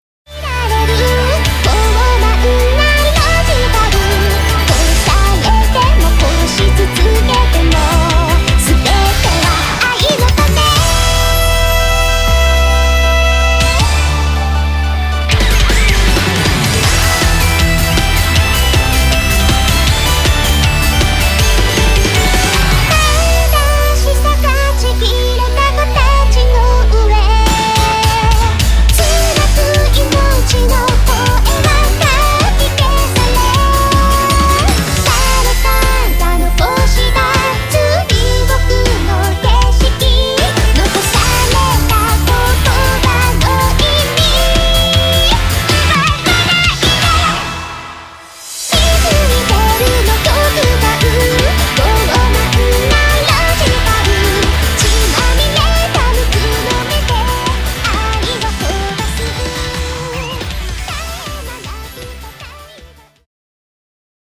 これぞデジタルロック！！って感じのトラックです。
ちなみに全篇ギブソンのヒスコレを弾きまくりんぐです。
シンセサウンド全開なのにとってもヘヴィ。